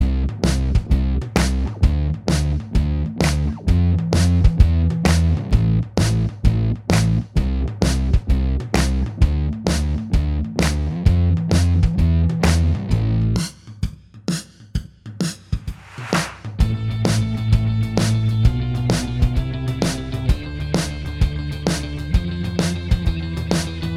Minus Lead Guitars Pop (2000s) 2:58 Buy £1.50